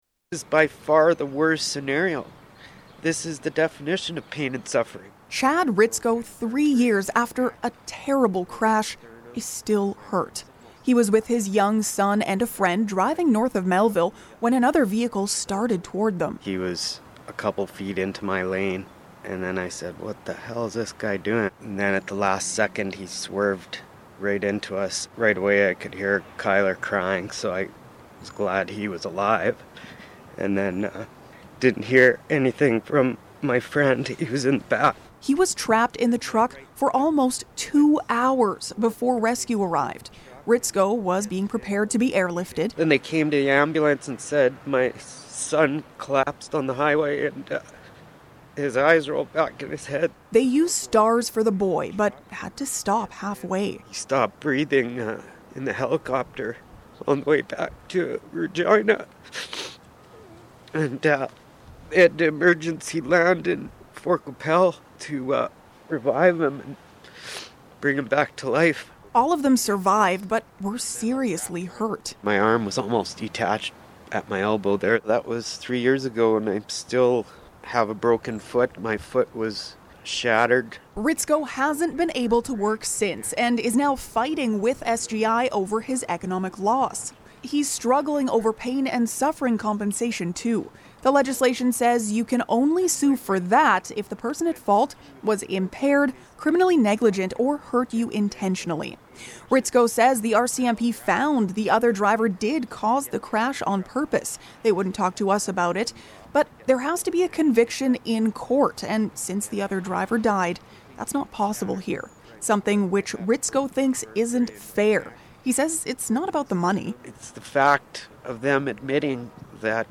cjme-feature-news.mp3